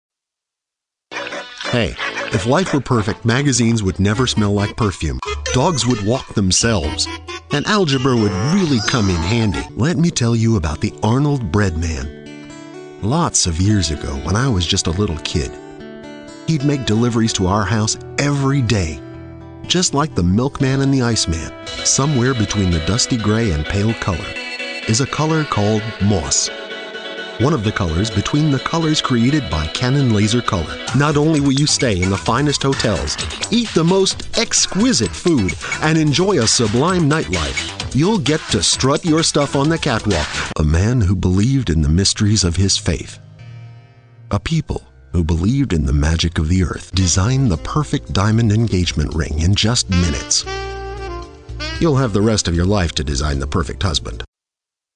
Voice of reason, warm, real, clear friendly, articulate, clear, versatile, and confident. Paternal, warm, conversational, versatile, and confident... and professional. Home studio & phone patch for direction via Skype.
Sprechprobe: Sonstiges (Muttersprache):